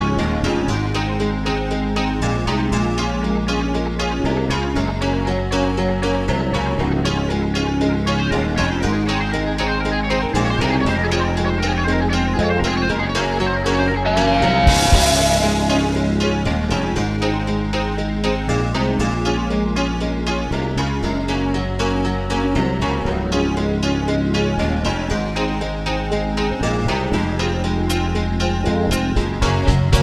No Guitars With Backing Vocals Rock 4:26 Buy £1.50